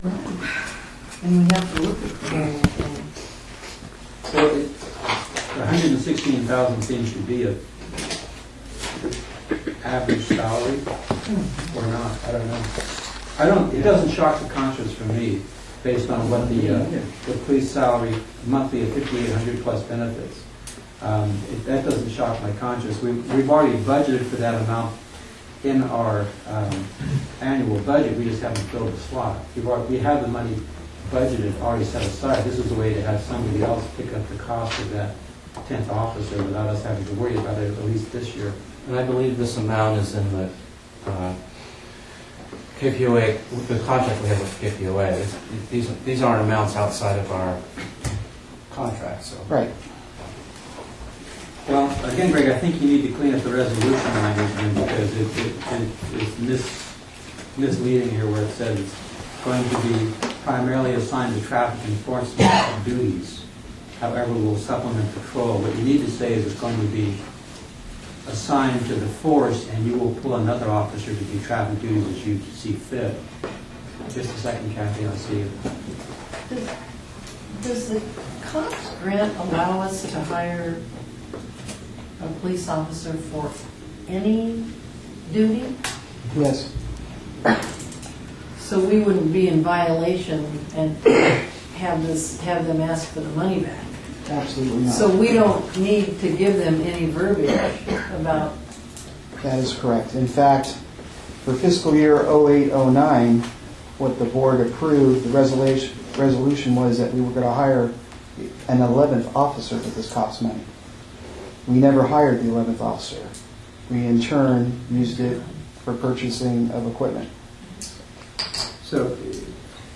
KPPCSD Board Meeting
Board meetings are held once monthly, on the second Thursday of the month.